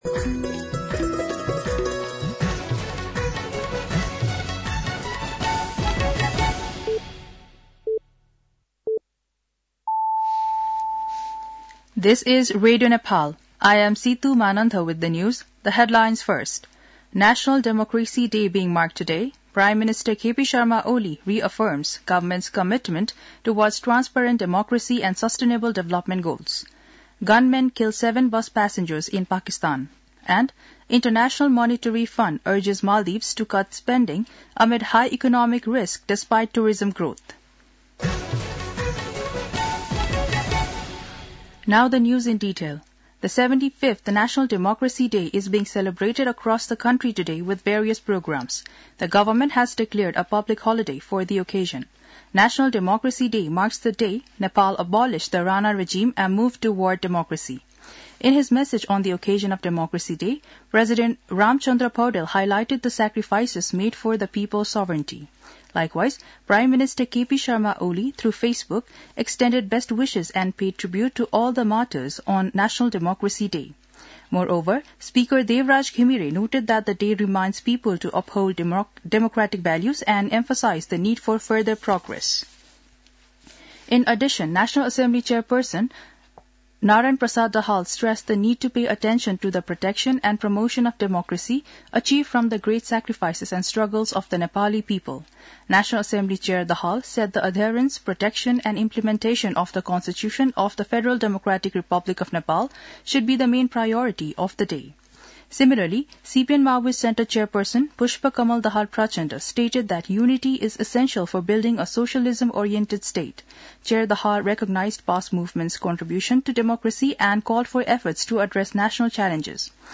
दिउँसो २ बजेको अङ्ग्रेजी समाचार : ८ फागुन , २०८१
2-pm-English-News-11-07.mp3